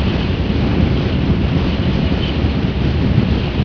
wind23.ogg